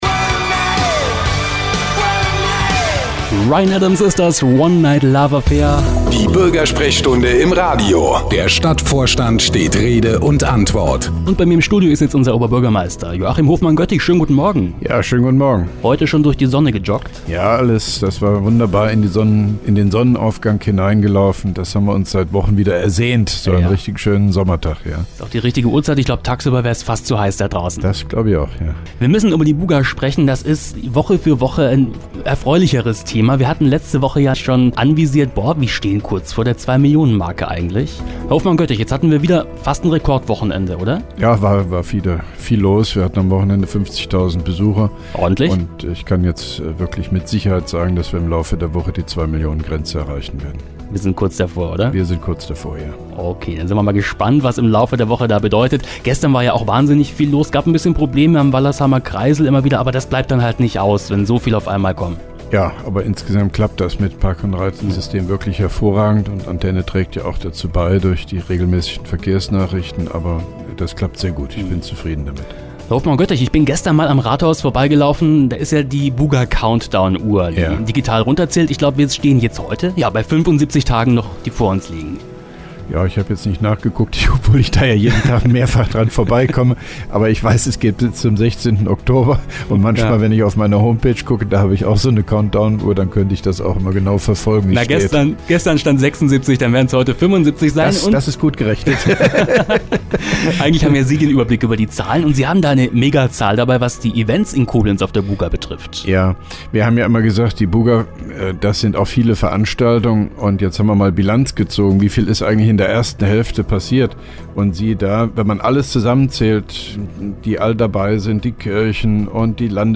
(1) Koblenzer Radio-Bürgersprechstunde mit OB Hofmann-Göttig 02.08.2011